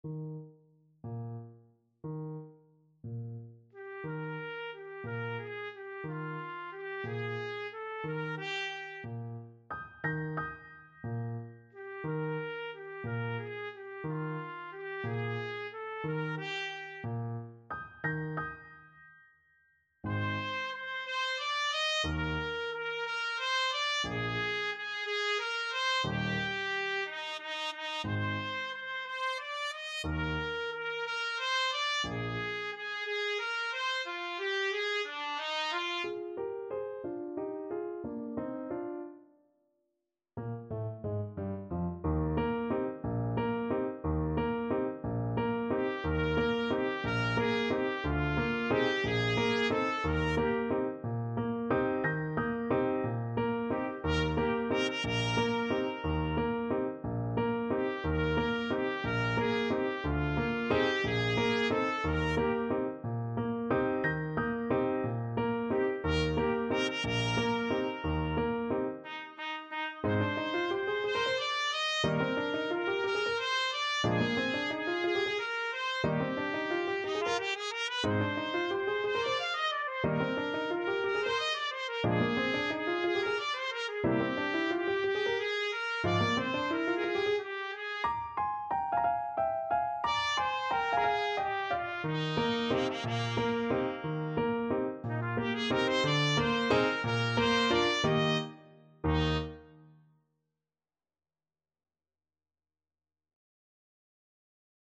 Trumpet version
6/8 (View more 6/8 Music)
Pochissimo pi mosso = 144 . =60
Classical (View more Classical Trumpet Music)